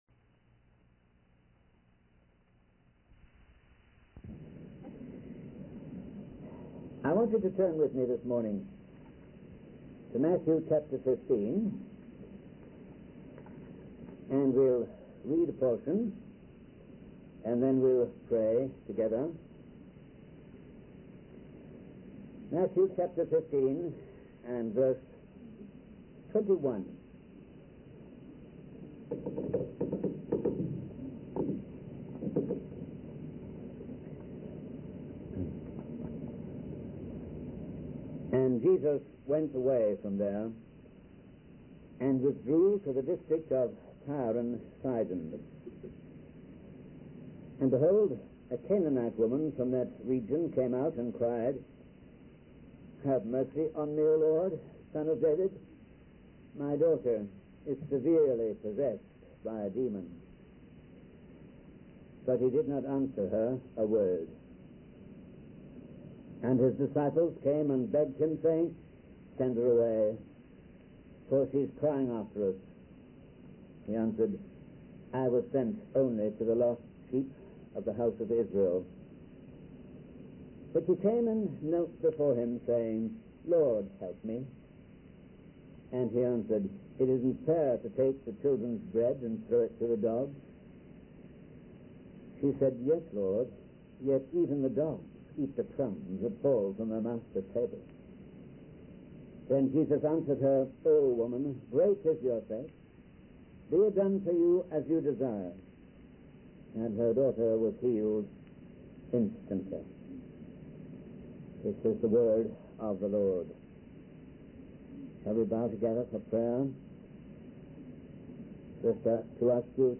In this sermon, the preacher focuses on the story of a woman who approached Jesus for help. He highlights four different responses Jesus gave to her, which were denial, discouragement, disillusionment, and deliverance.